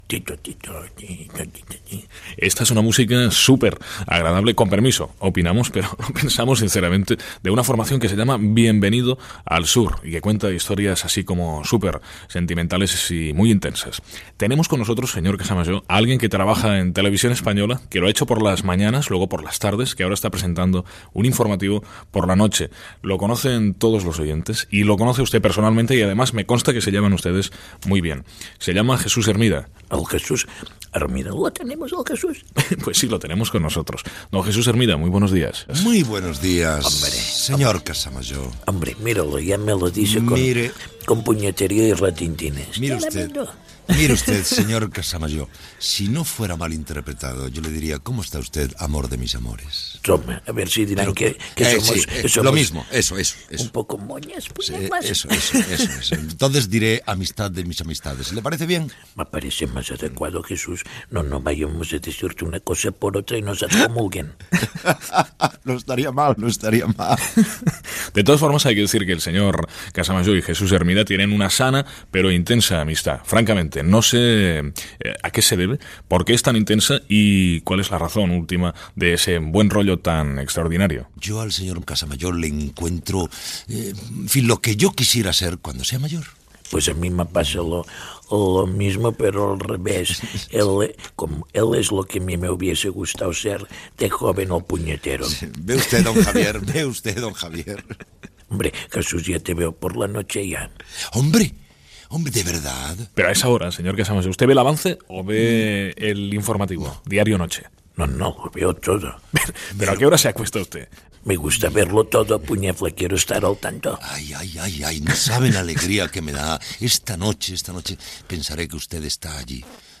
Fragment d'una entrevista al periodista Jesús Hermida
Entreteniment